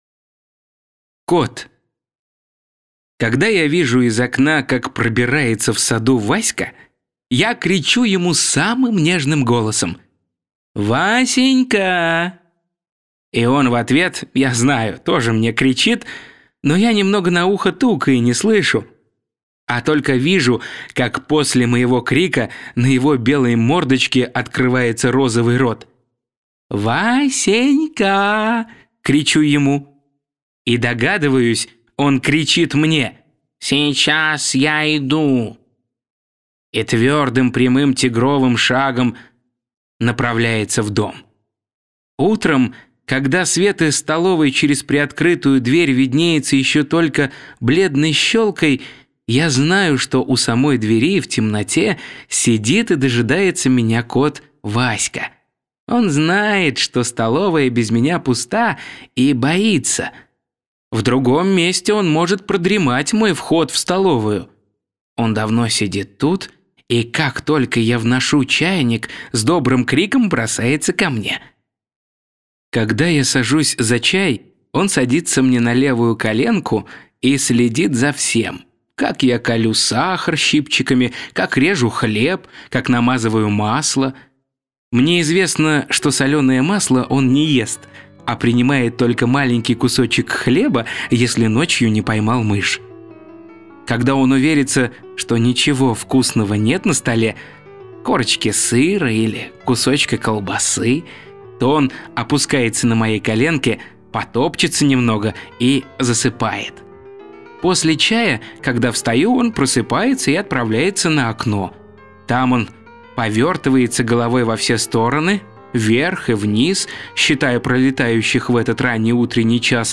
Кот - аудио рассказ Пришвина - слушать онлайн | Мишкины книжки